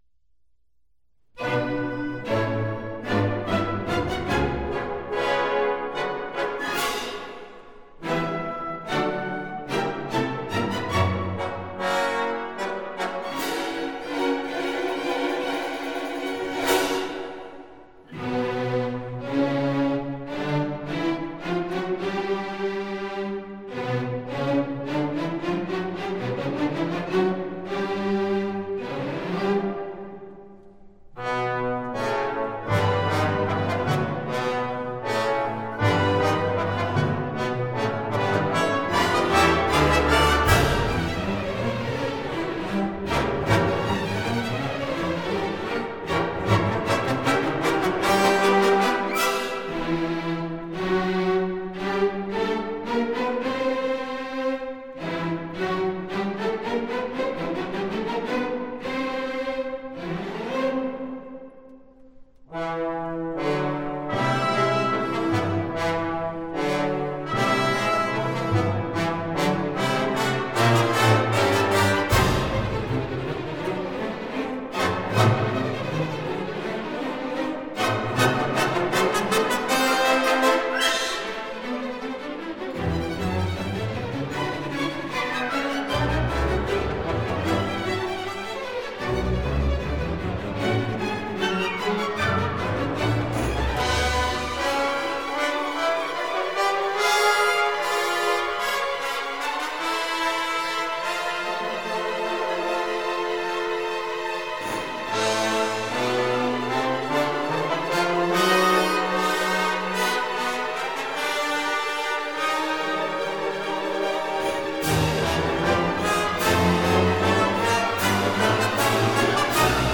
音乐类型：古典